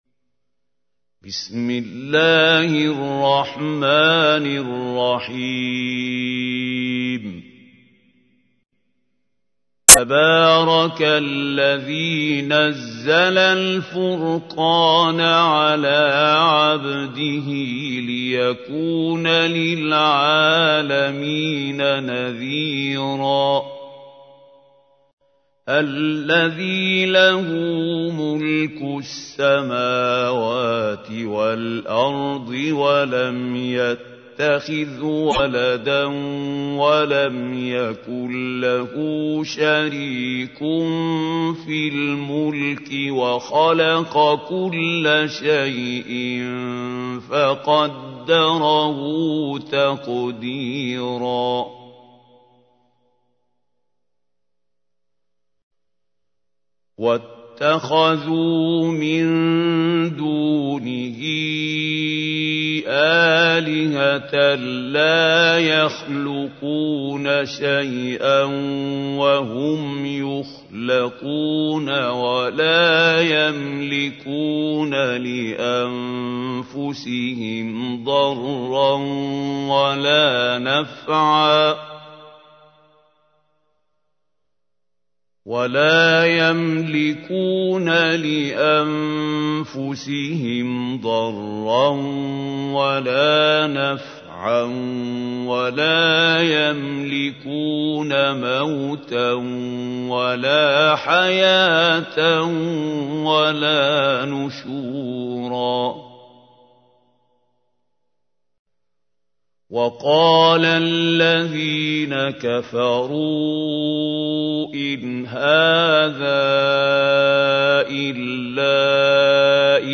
تحميل : 25. سورة الفرقان / القارئ محمود خليل الحصري / القرآن الكريم / موقع يا حسين